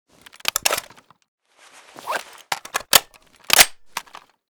scar_reload_empty.ogg.bak